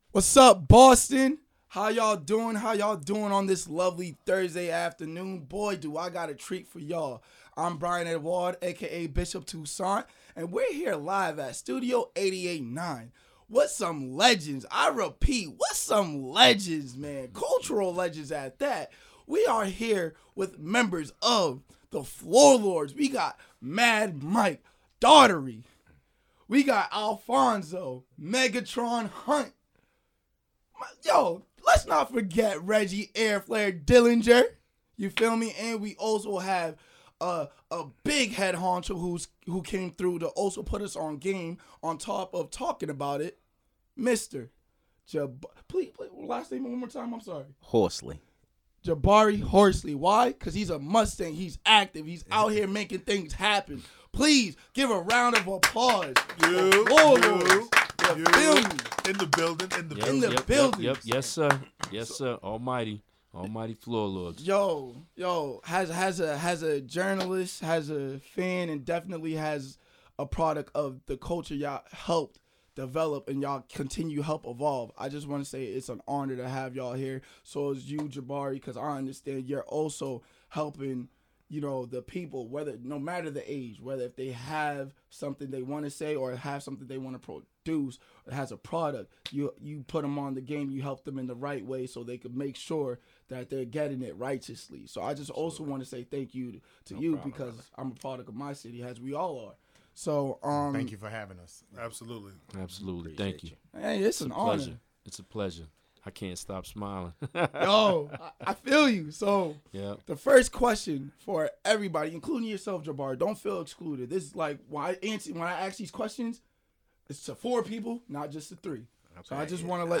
ERS+ Interviews The Floor Lords, Global Hip-Hop Ambassadors - WERS 88.9FM